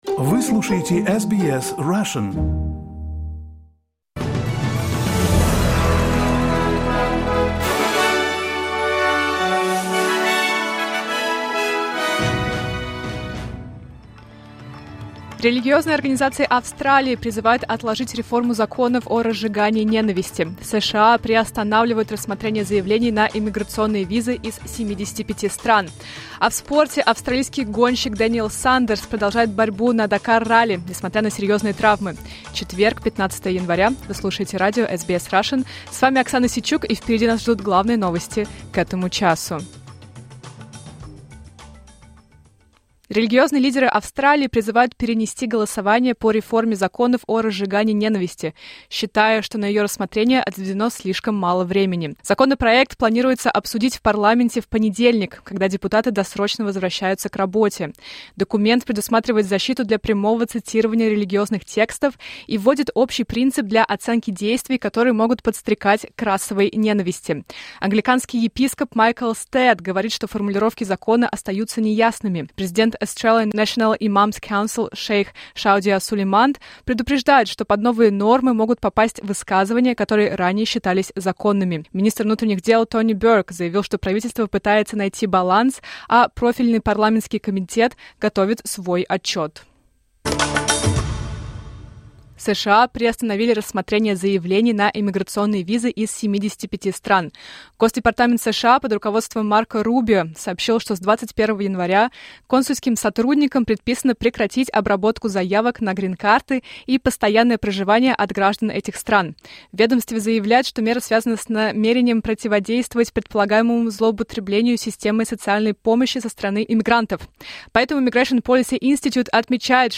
Новости SBS на русском языке — 15.01.2026